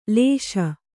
♪ lēśa